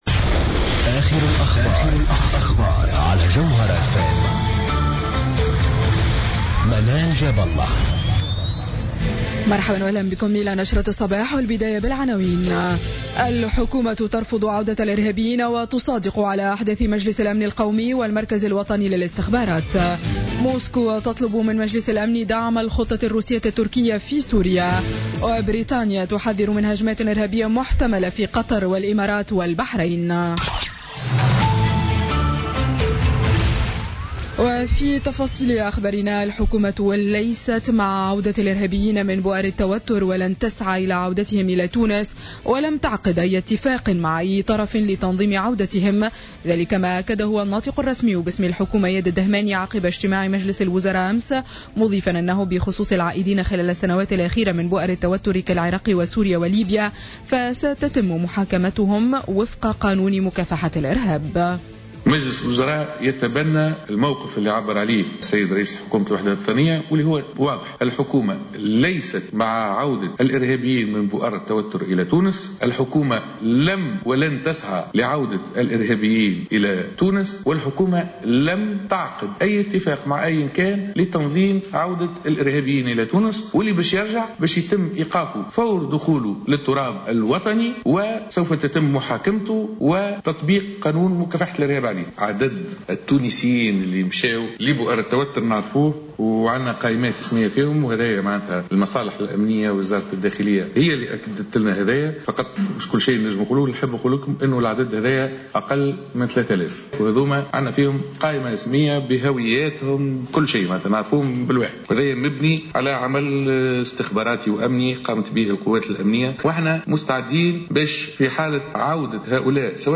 نشرة أخبار السابعة صباحا ليوم السبت 31 ديسمبر 2016